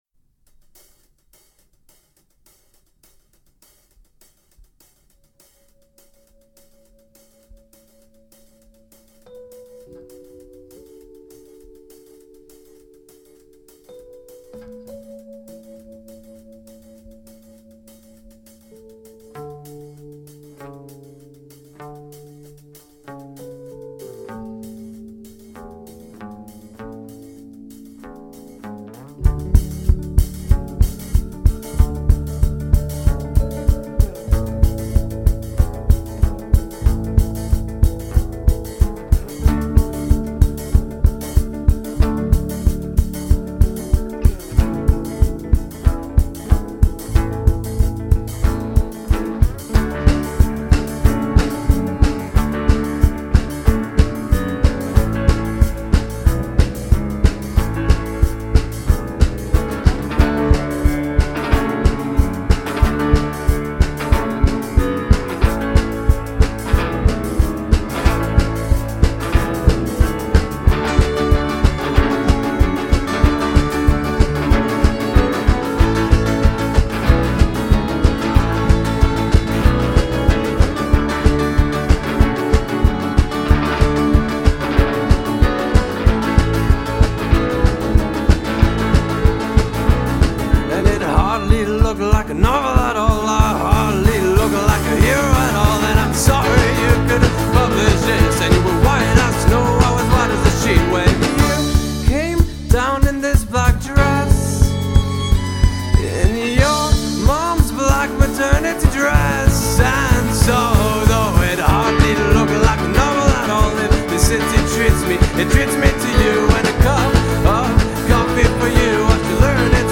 hanno begli arrangiamenti arricchiti da fiati e archi